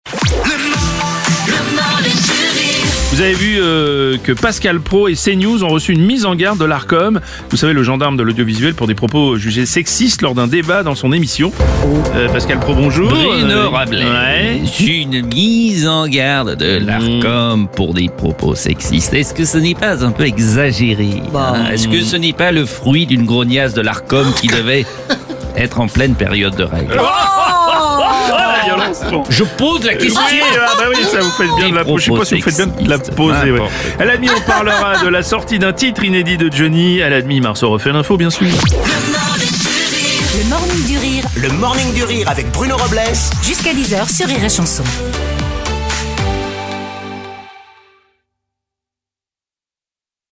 L’imitateur
en direct à 7h30, 8h30, et 9h30.